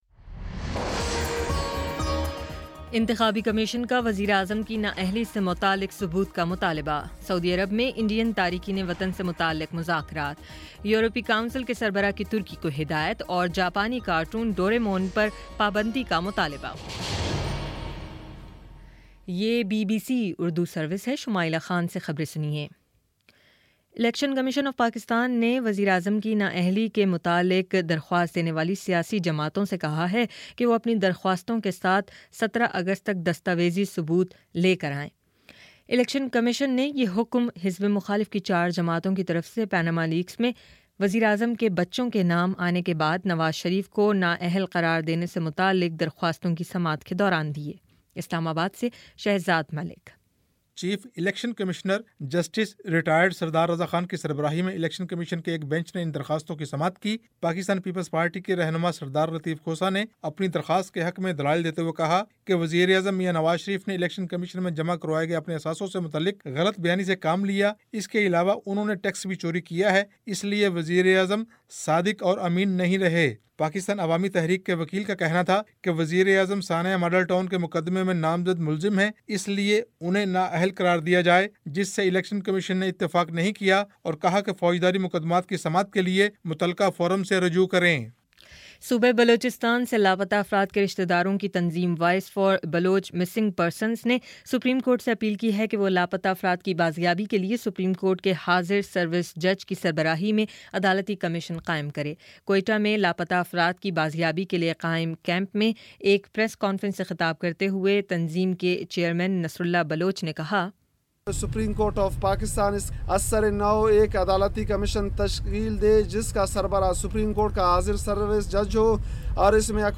اگست 03 : شام سات بجے کا نیوز بُلیٹن